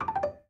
dm_received.ogg